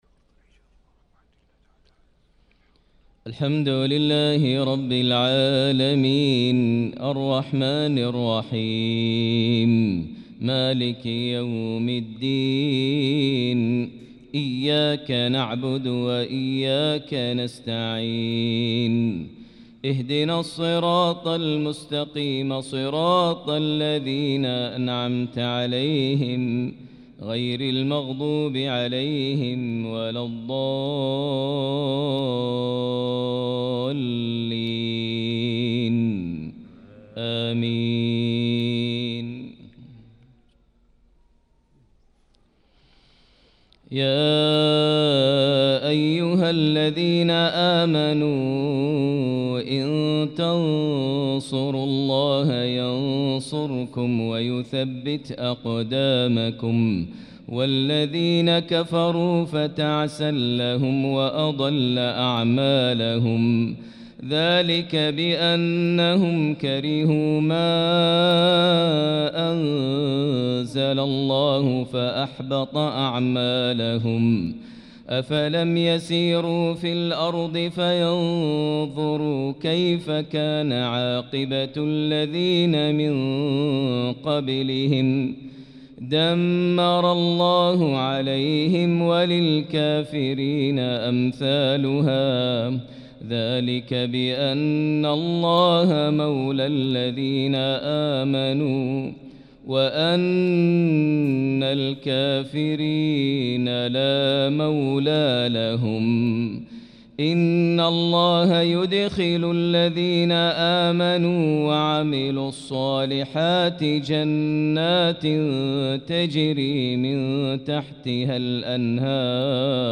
صلاة العشاء للقارئ ماهر المعيقلي 5 شوال 1445 هـ
تِلَاوَات الْحَرَمَيْن .